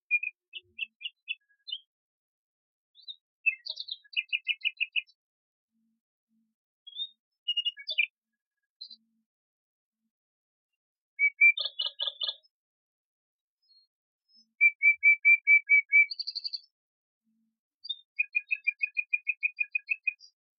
Hier klicken und hören wie die Zeit klingt.
• Wanduhr